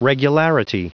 Prononciation du mot regularity en anglais (fichier audio)
Prononciation du mot : regularity